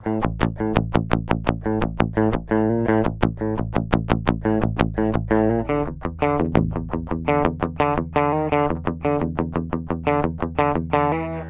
Атака бронебойная , как молотком в лоб))